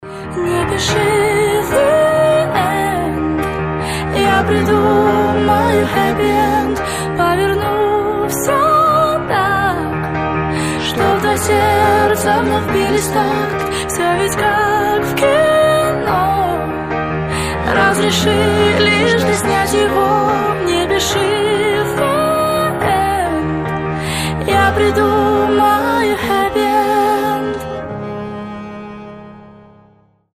красивые
мелодичные
спокойные
дуэт
скрипка
лиричные
Контрабас